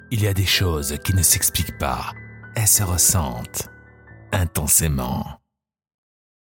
Male
Assured, Authoritative, Character, Confident, Cool, Corporate, Deep, Engaging, Natural, Reassuring, Smooth, Soft, Warm, Versatile, Young
Microphone: se electronics x1s